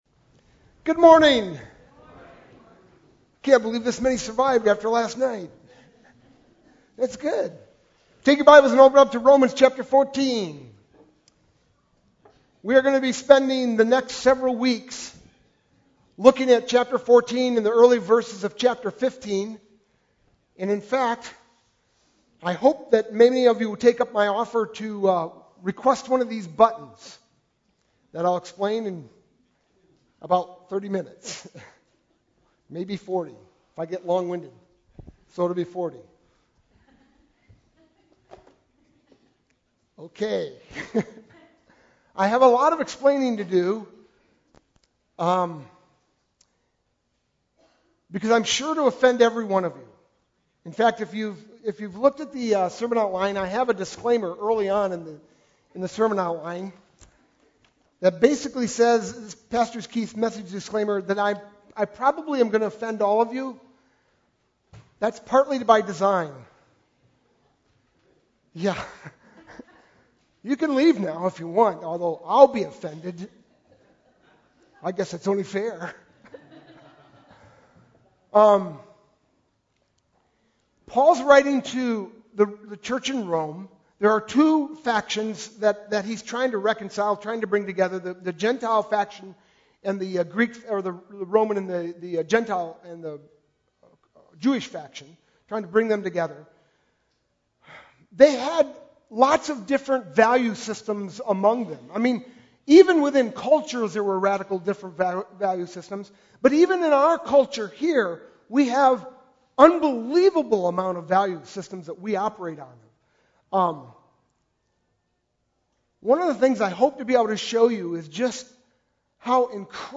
sermon-2-5-12.mp3